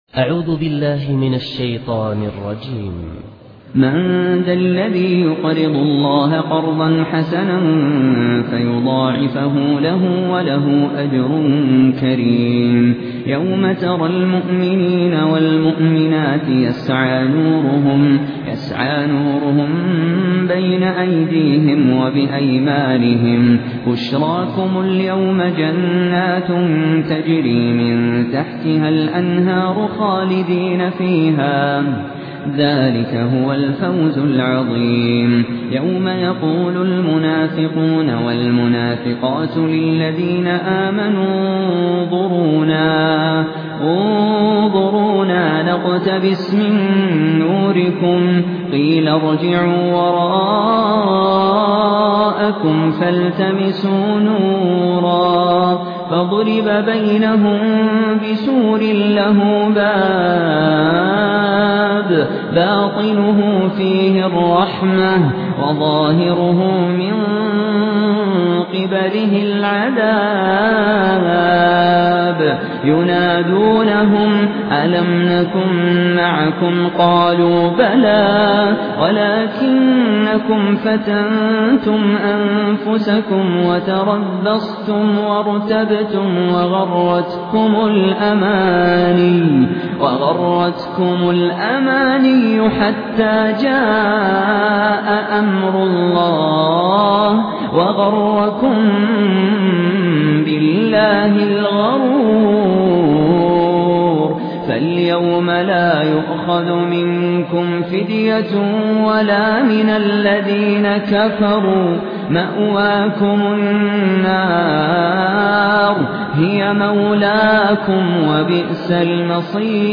Quran recitations
Humble, distinctive recitations